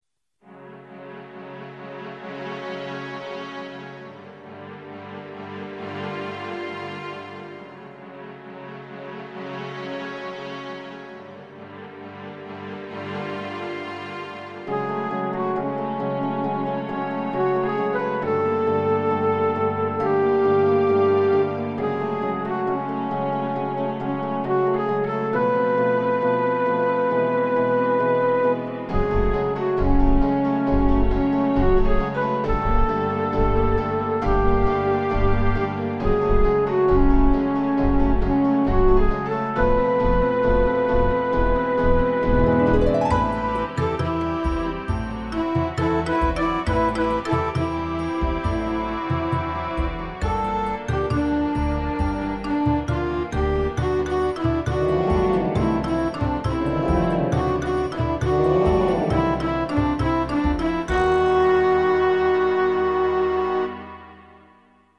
Banda Sonora del cortometraje